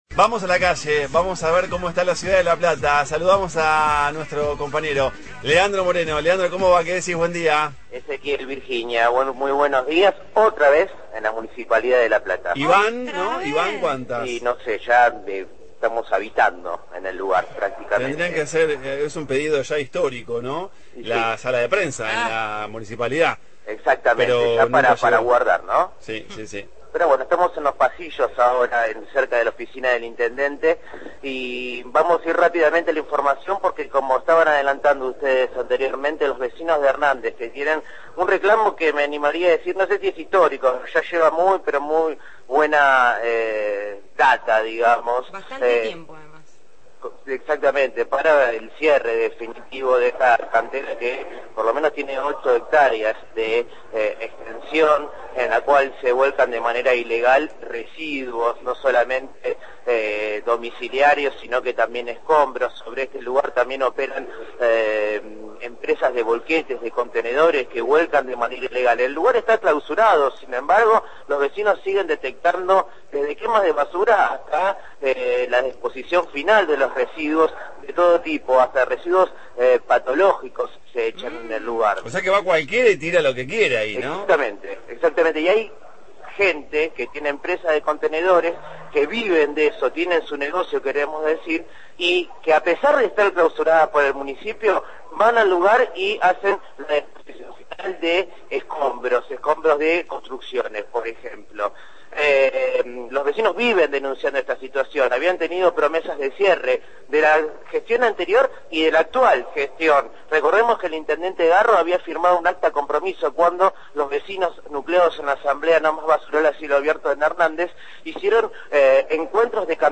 MÓVIL/ Reunión entre Garro y vecinos de «No + basural» – Radio Universidad